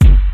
Kick (Good Life).wav